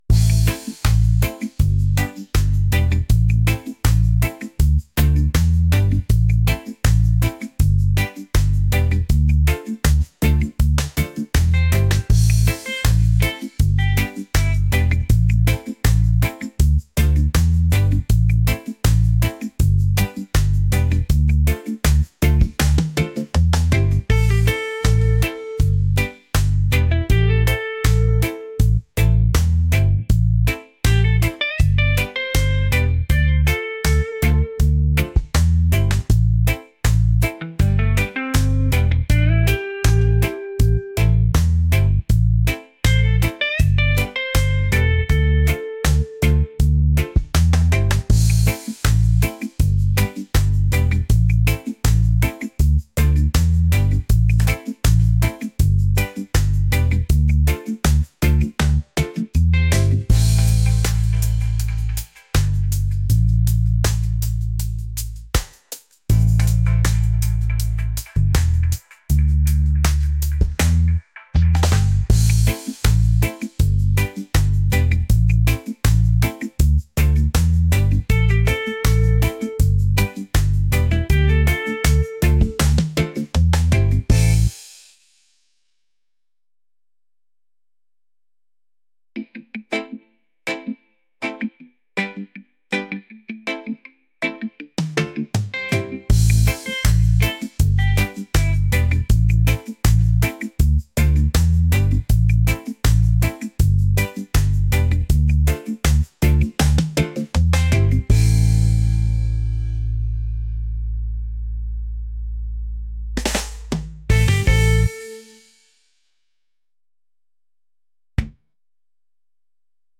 laid-back | groovy | reggae